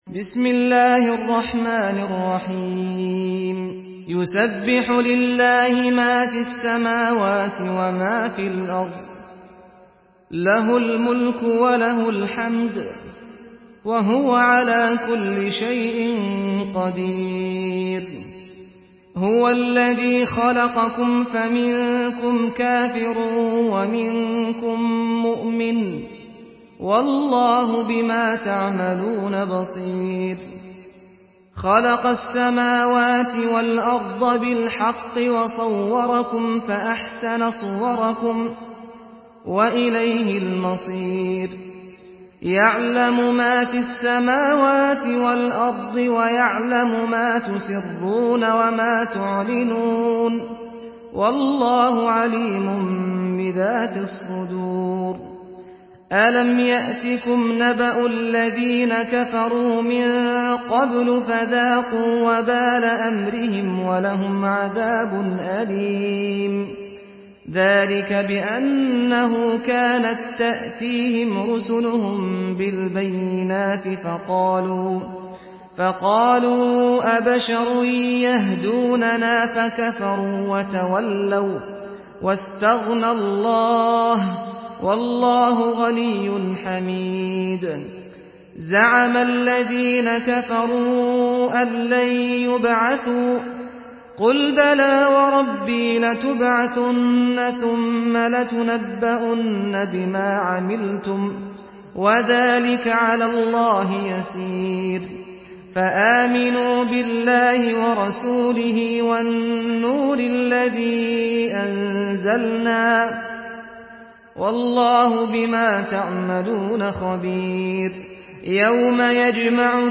سورة التغابن MP3 بصوت سعد الغامدي برواية حفص
مرتل